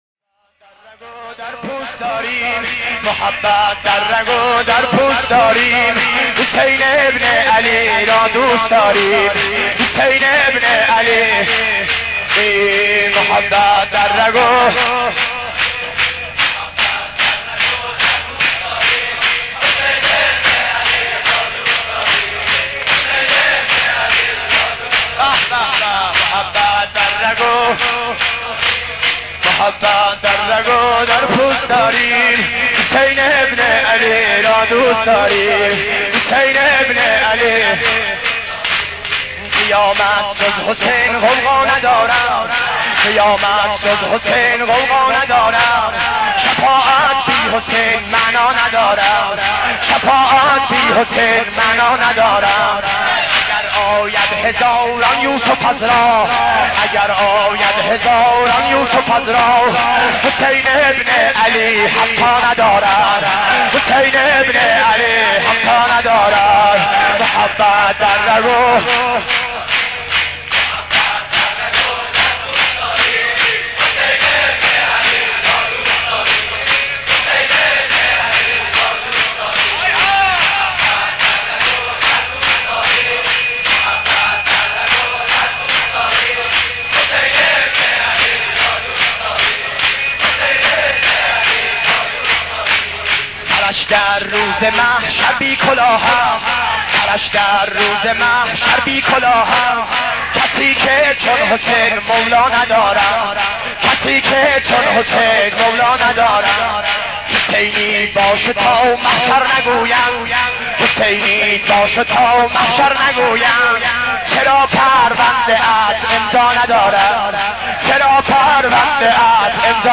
متن سینه زنی شور شهادت امام حسین (ع)